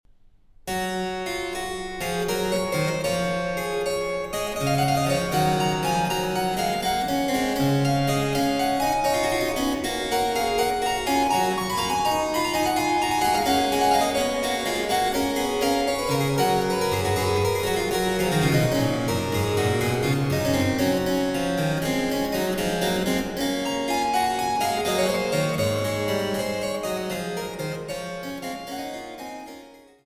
Cembalo